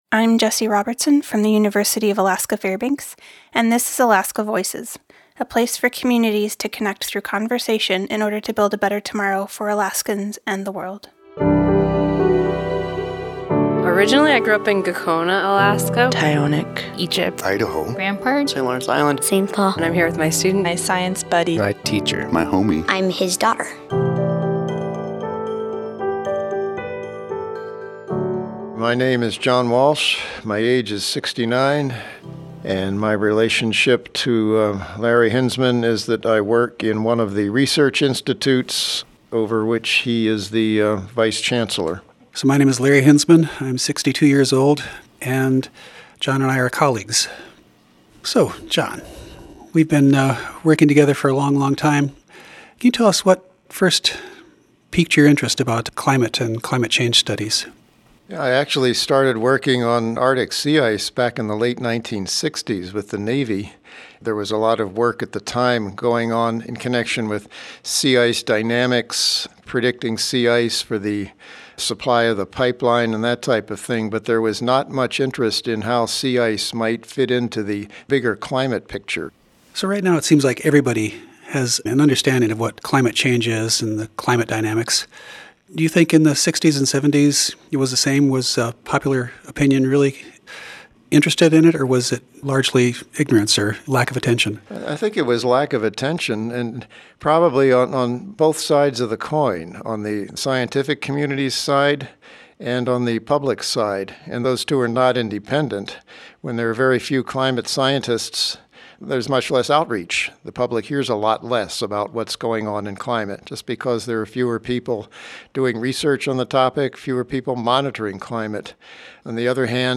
This interview was recorded in collaboration with StoryCorps.
Music: "Arctic Evening" by Marcel du Preez